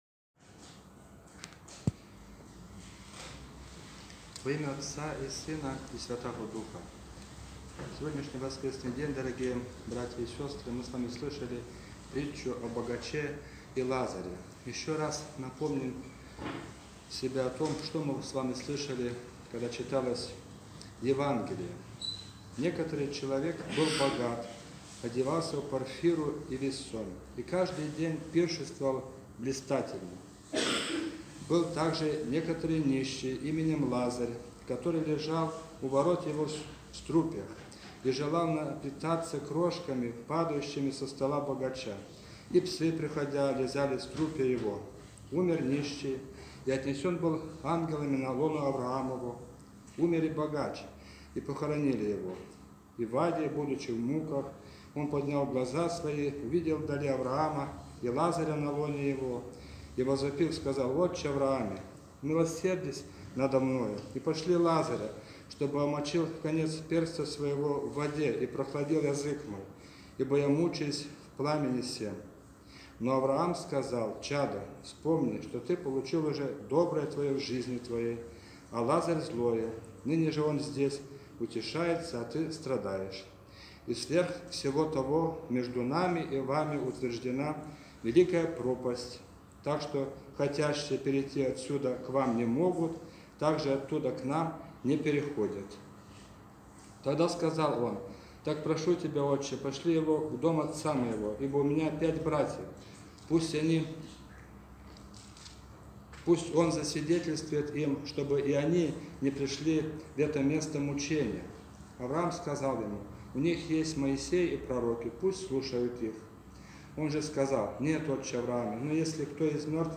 Воскресная проповедь